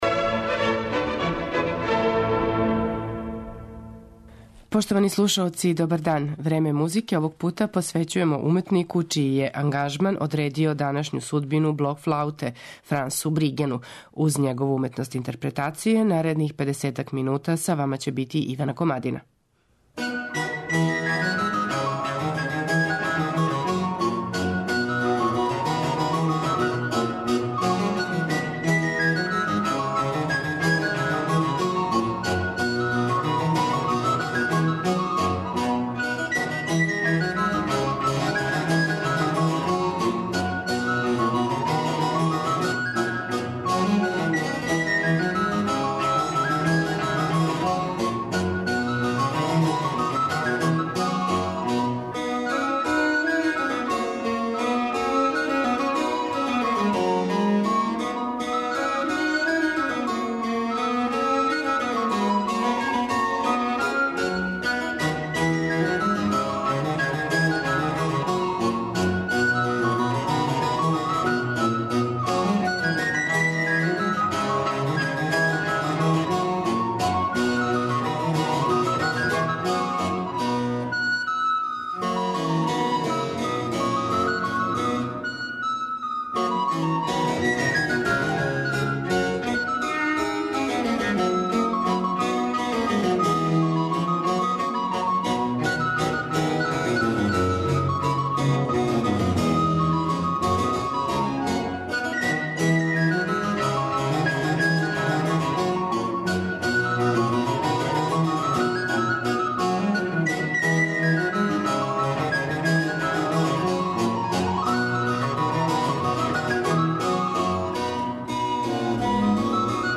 Судбина блок-флауте као музичког инструмента била би потпуно другачија без невероватних напора и снажног утицаја Франса Бригена.
Овај холандски уметник, коме смо посветили данашње Време музике, није само највећи виртуоз блок-флауте, већ и најзначајнији педагог који је поставио стандарде модерног свирања на овом инструменту и своје знање поделио са студентима широм света.
У његовој интерпретацији слушаћемо дела Јохана Себастијана Баха и француских мајстора барока: Жозефа Бодина де Боамартјеа, Филибера де Лавиња, Франсоа Купрена и Луј-Антоана Дорнела.